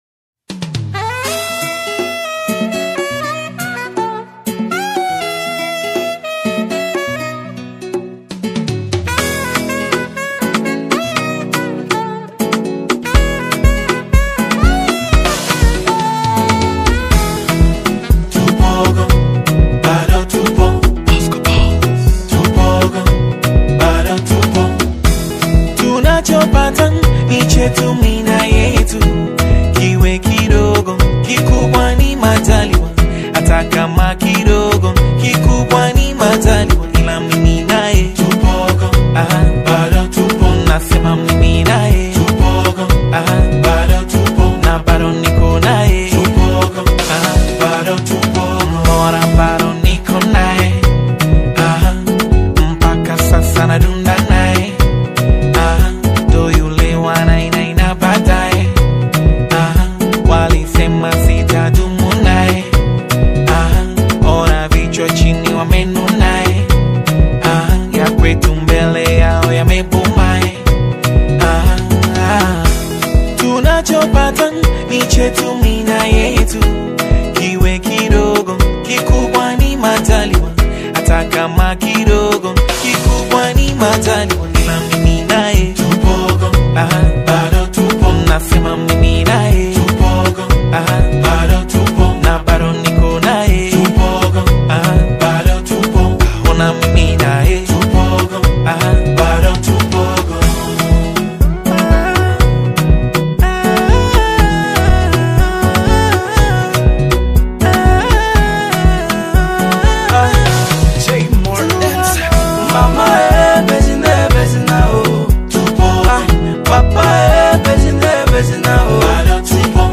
energetic Bongo Fleva banger
smooth vocals and catchy melodies